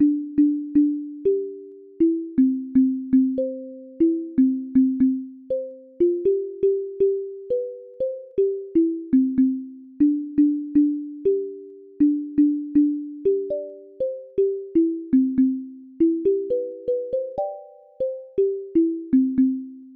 Ring-a bell.wav